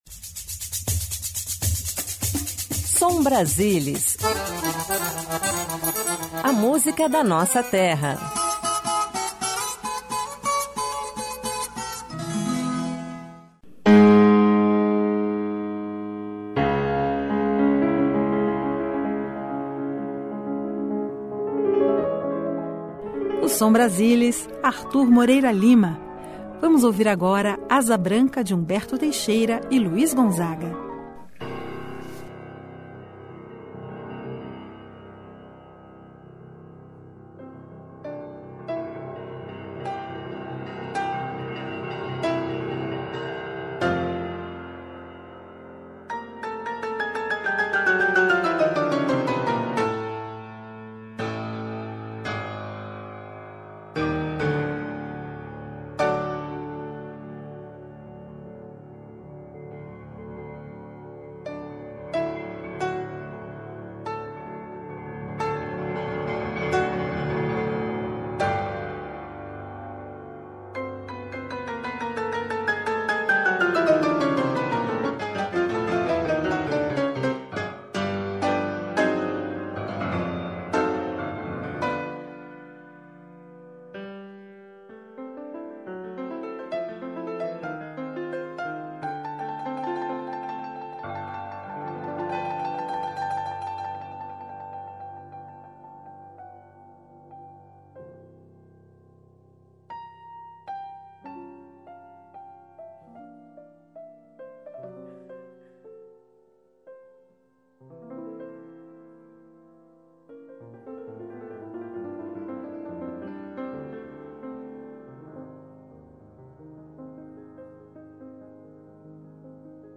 Piano Música Erudita
Choro